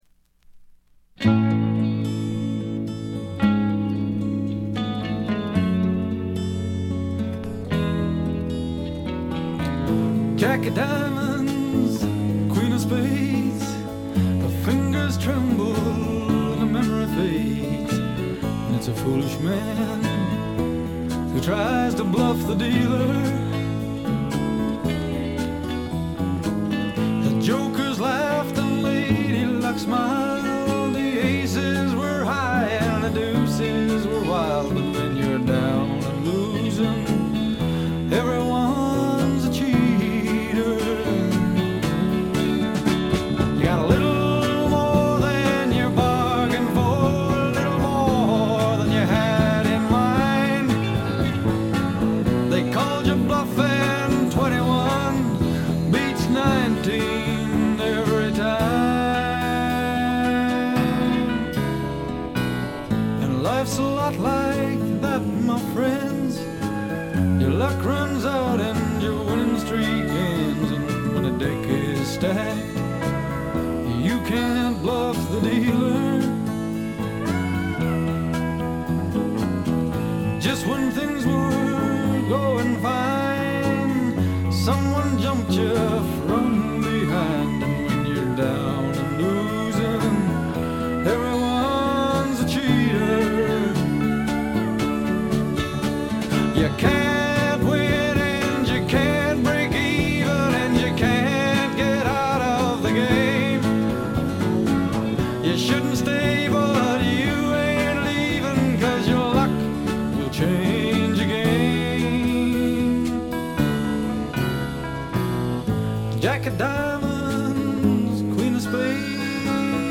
カナディアンらしくカントリー臭とか土臭さはあまり感じません。
試聴曲は現品からの取り込み音源です。